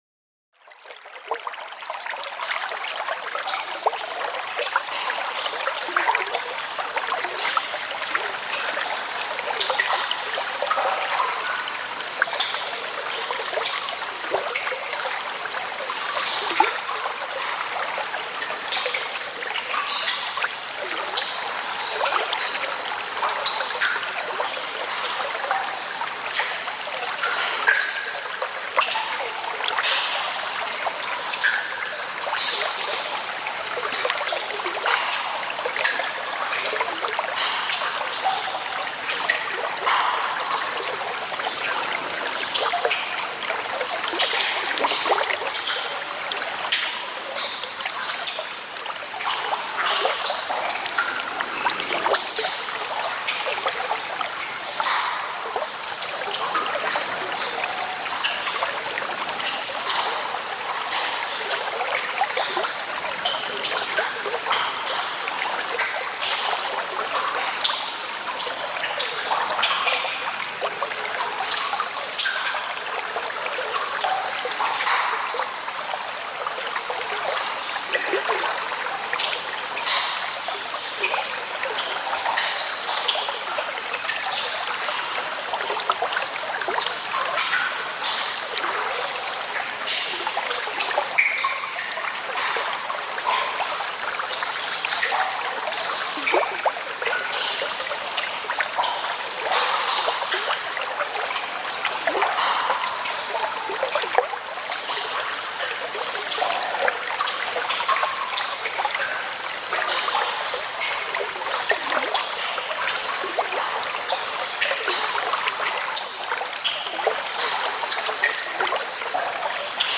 MYSTISCHE TIEFE: Höhlenbach-Echo mit hallenden Tropfen